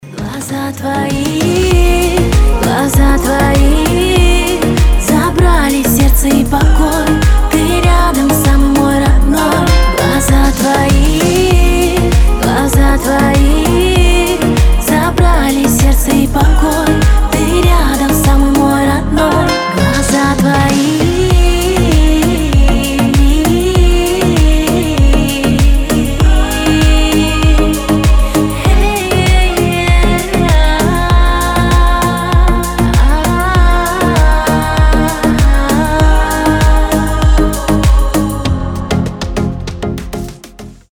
красивые
женский вокал
восточные мотивы